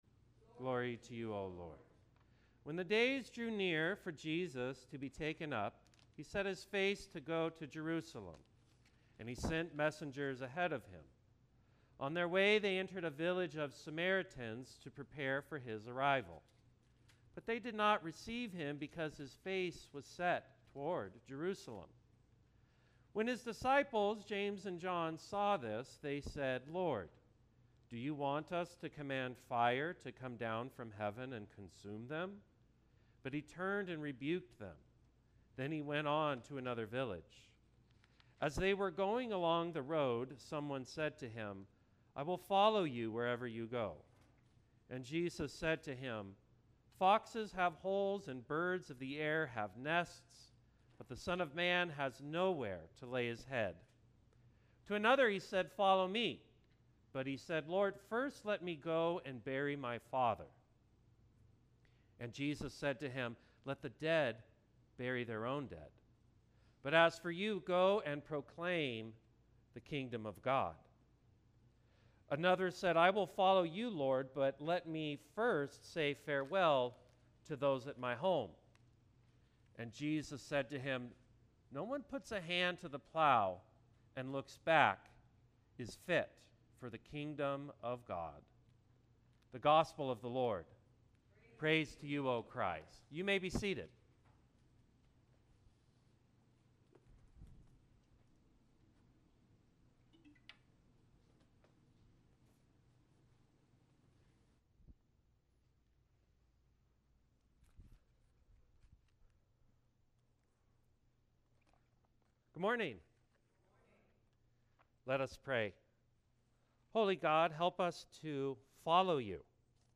Sermon 06.29.25